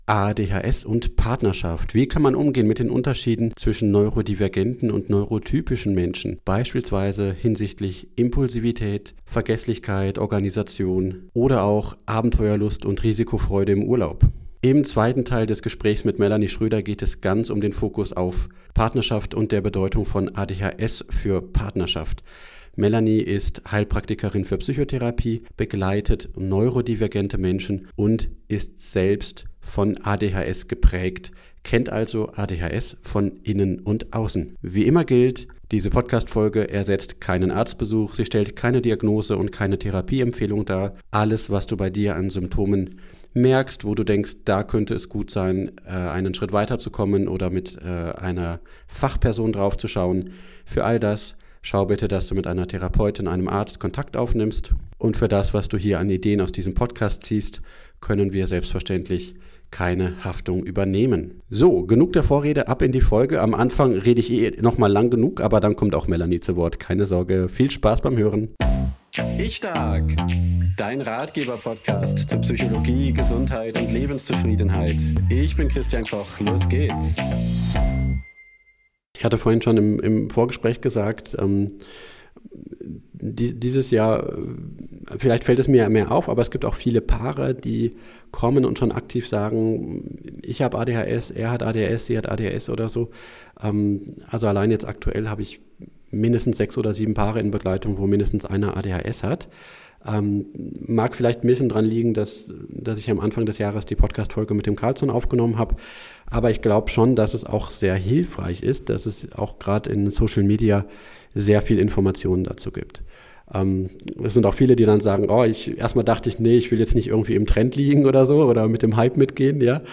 Es handelt sich um die Fortsetzung unseres ADHS-Gesprächs aus der letzten Folge.